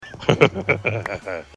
DarkLaugh